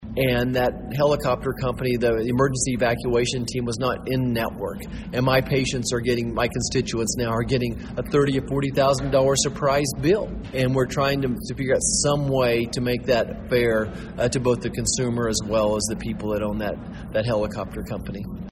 Touting a message of optimism for Kansas farmers, 1st District Congressman and current Republican Senate candidate Roger Marshall spoke to a gathering at the annual Young Farmers & Ranchers Leaders Conference Saturday at the Manhattan Conference Center.